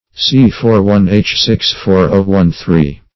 digitoxin \dig`i*tox"in\ (d[i^]j`[i^]*t[o^]k"s[i^]n), n. (Chem.)